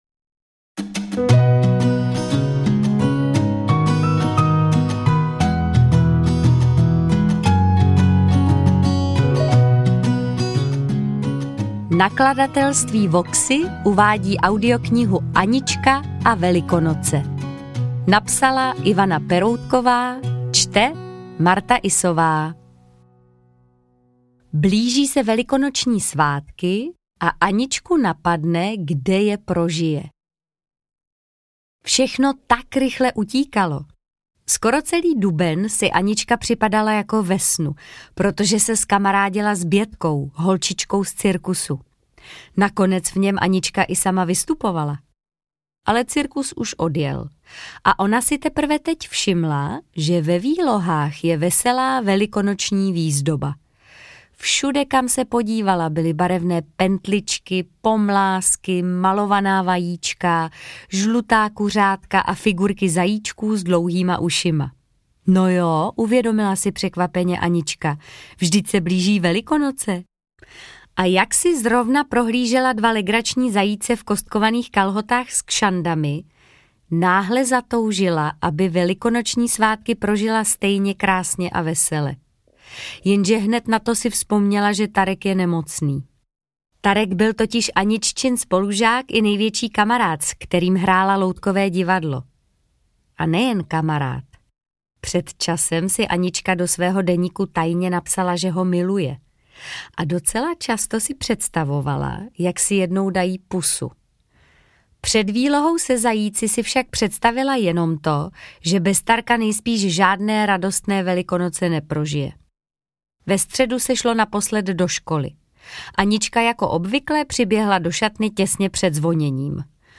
Interpret:  Martha Issová